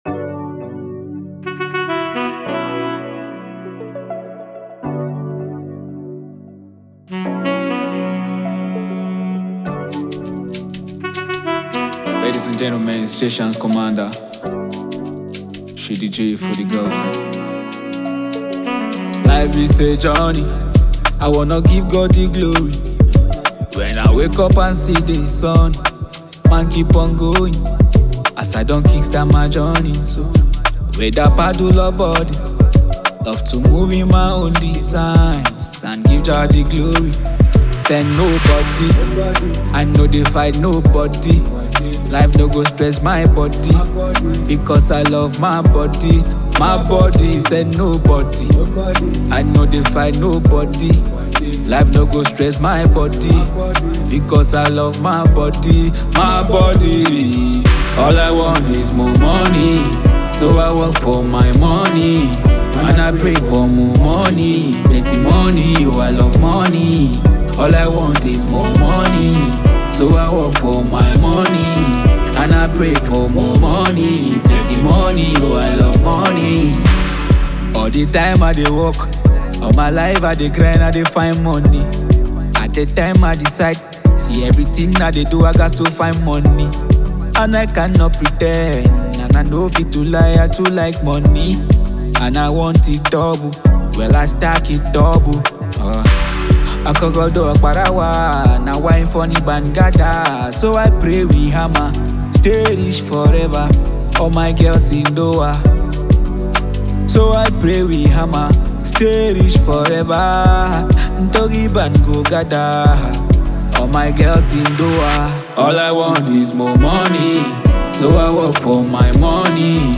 Afro pop
pop tune